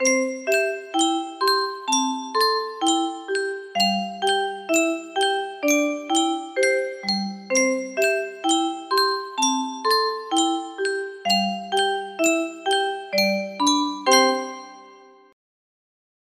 Yunsheng Music Box - Unknown Tune 1055 music box melody
Full range 60